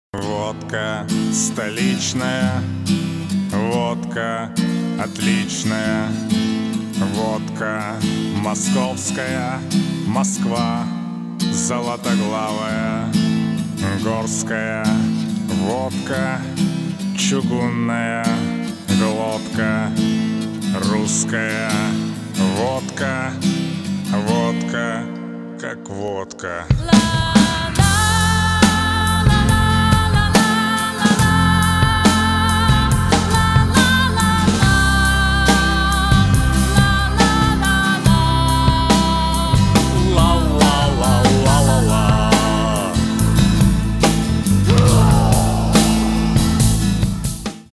• Качество: 320, Stereo
гитара
мужской вокал
женский вокал
веселые
спокойные